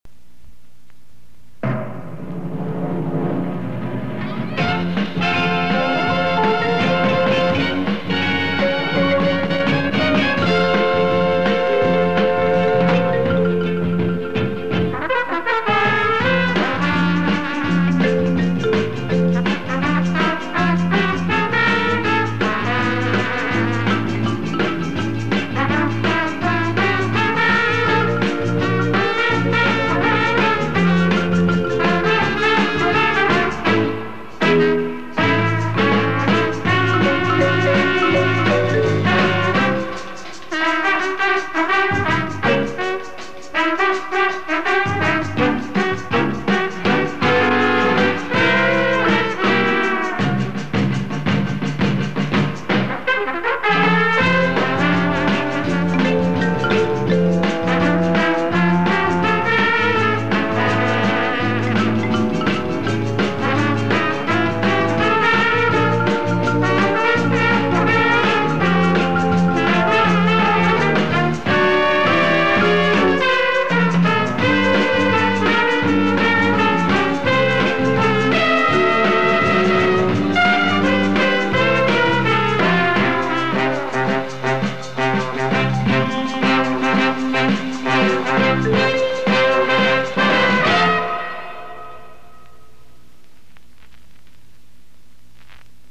Goosebury State Marching Band
Goosebury-Fight-Song.mp3